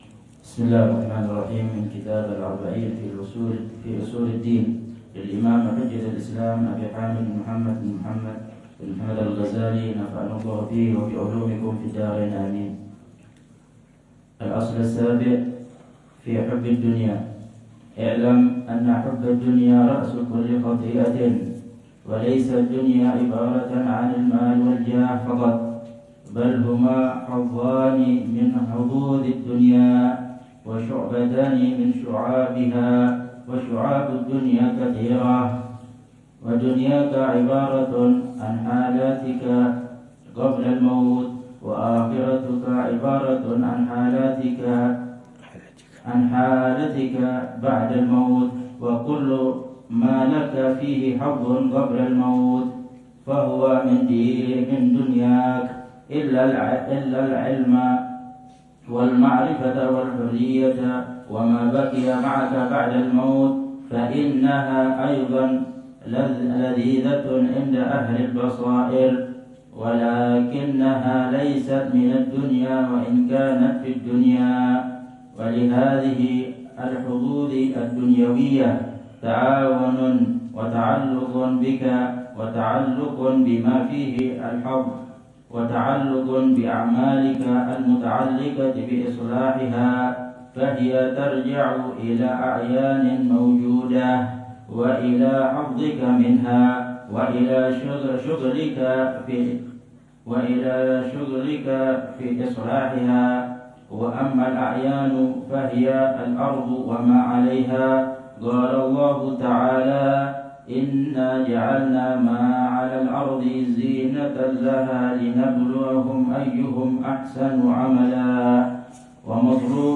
الدرس السابع والعشرون للعلامة الحبيب عمر بن محمد بن حفيظ في شرح كتاب: الأربعين في أصول الدين، للإمام الغزالي .